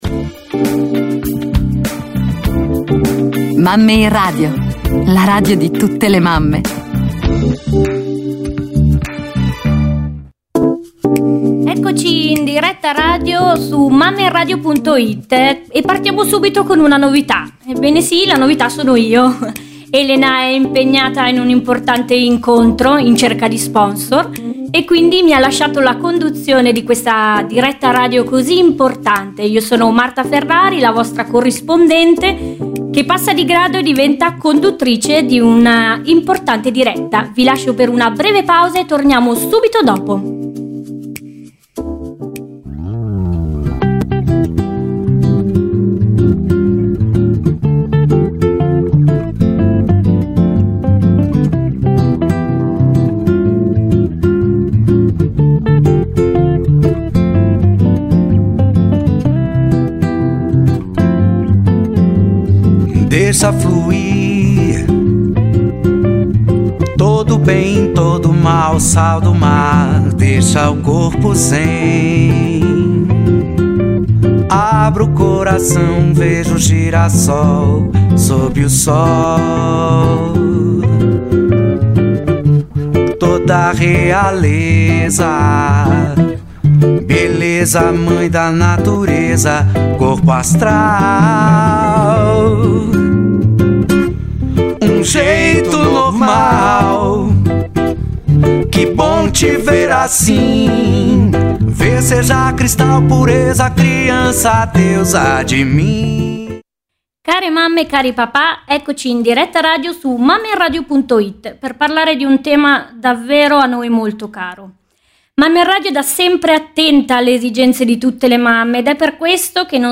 Depressione post partum: diretta su MammeInRadio - Depressione Post Partum